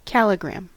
Ääntäminen
US : IPA : [ˈkæl.ɪ.ɡɹæm]